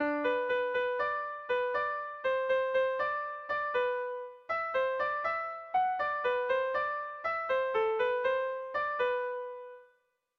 Lauko txikia (hg) / Bi puntuko txikia (ip)
A-B